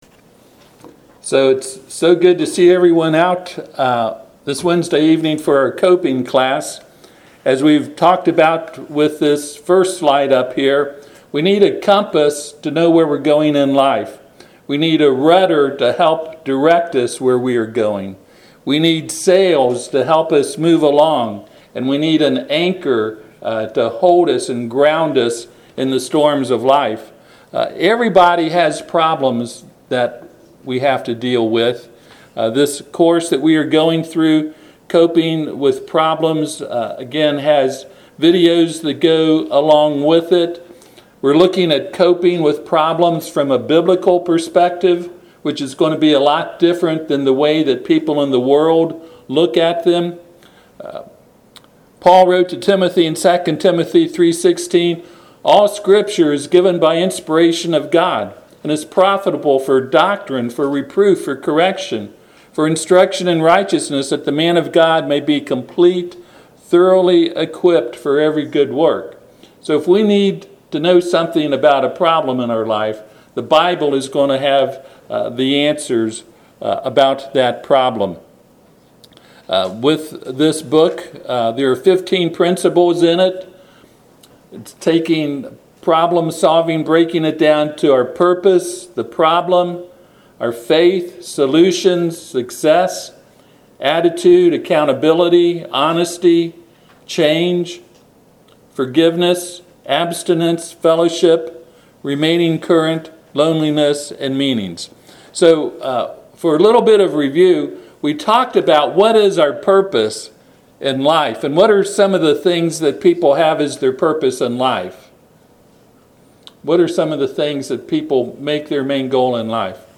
Service Type: Bible Study Class